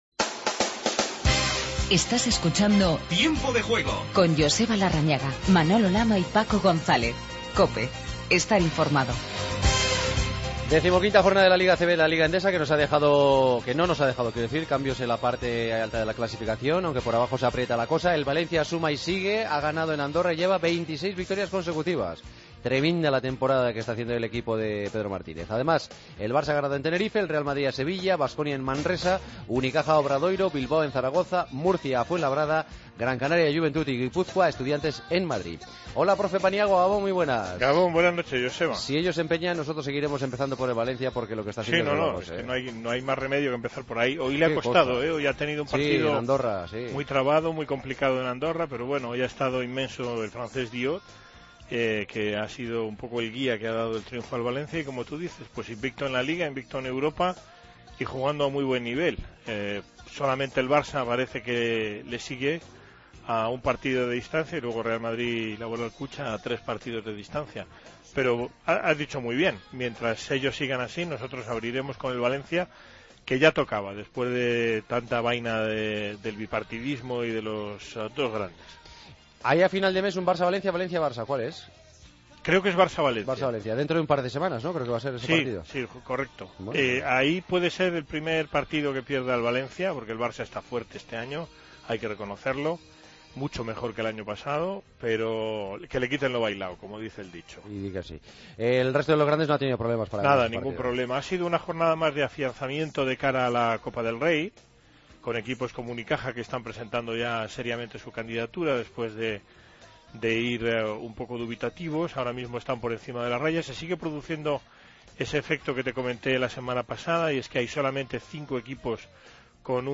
Repaso al resto de noticias y tuits deportivos del día. Cerramos con el Rap